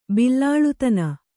♪ billa